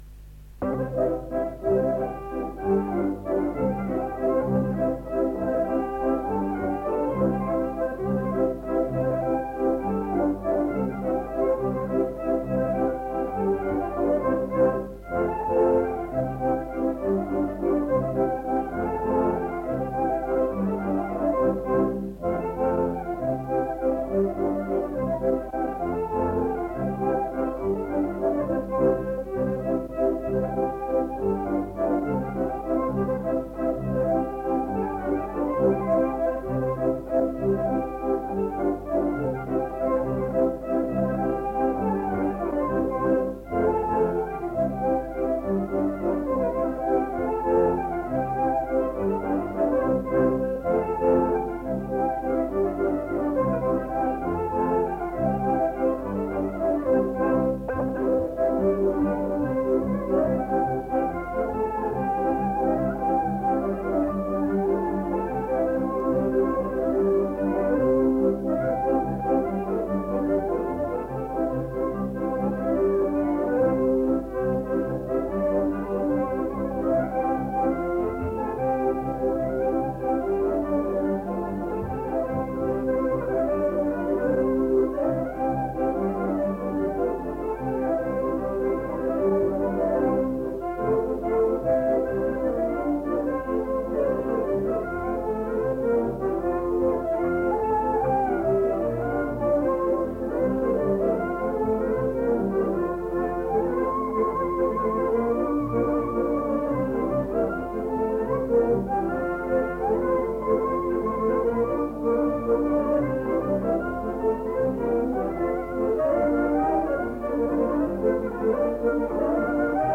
Instrumentalny 14 (cykl utworów) – Żeńska Kapela Ludowa Zagłębianki
Nagranie archiwalne